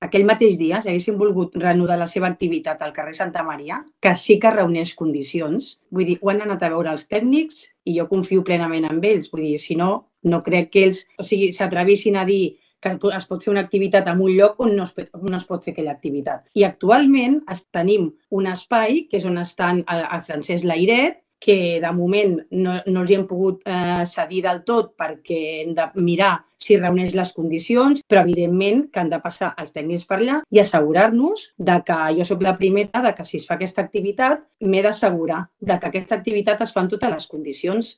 Des del govern, la regidora de Promoció de la Salut,